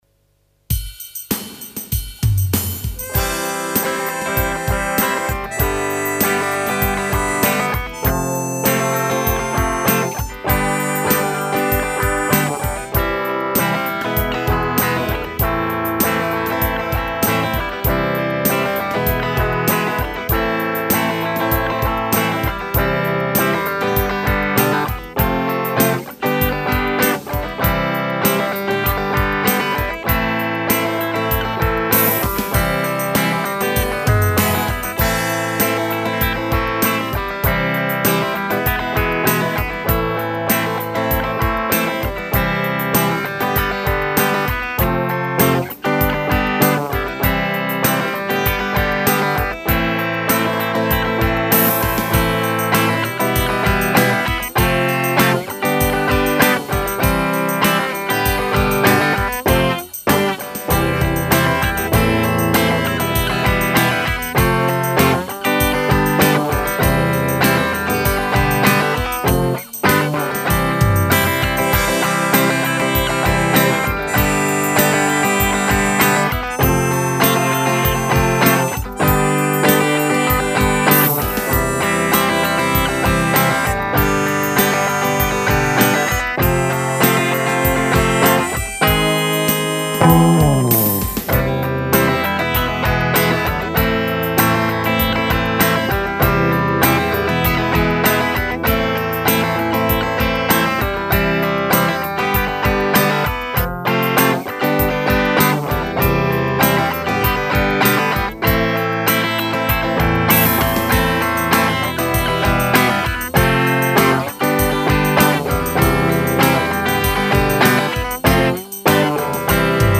מוזיקה מקפיצה..
אה שכחתי לציין שאני מנגנת בגיטרה החשמלית..
זה קצת חוזר על עצמו, אבל אני מבינה שזה קשור למילים כלשהם..